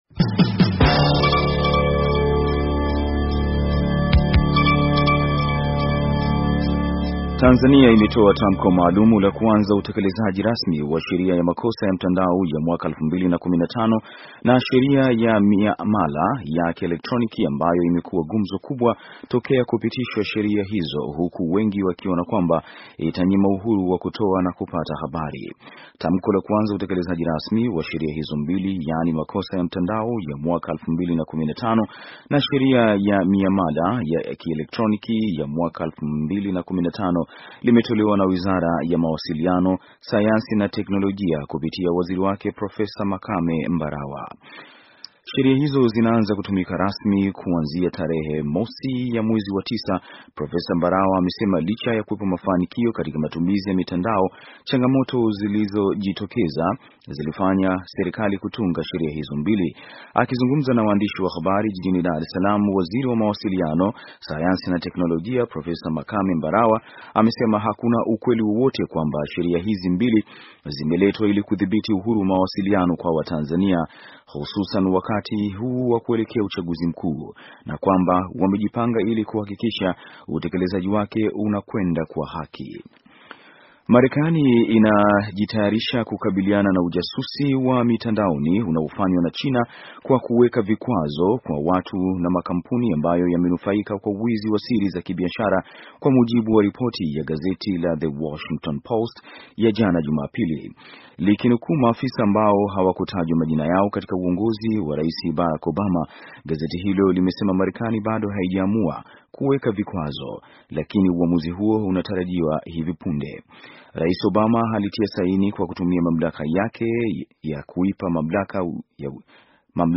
Taarifa ya habari - 5:35